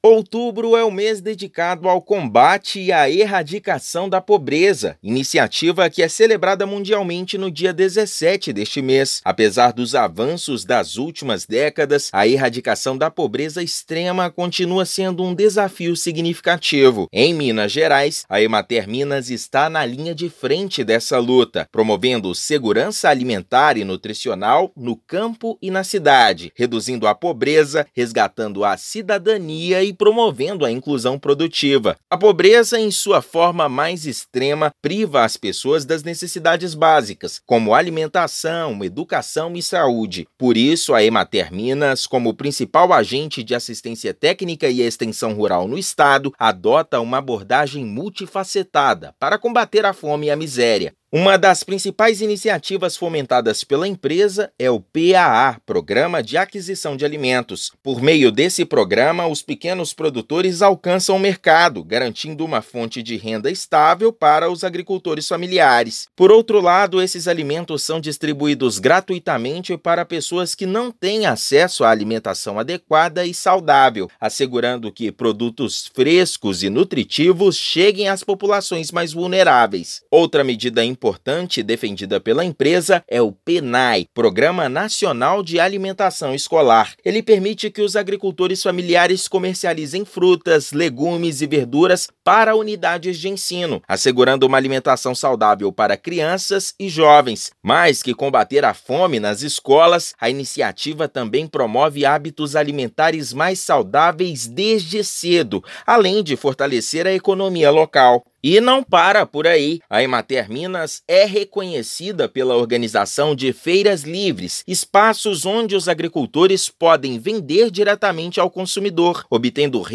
No mês em que a luta pela erradicação da pobreza ganha destaque, a Empresa de Assistência Técnica e Extensão Rural (Emater-MG) reforça a importância de programas como o Programa de Aquisição de Alimentos (PAA) e o Programa Nacional de Alimentação Escolar (Pnae) para garantir renda aos agricultores e alimentação saudável às comunidades mineiras. Ouça matéria de rádio.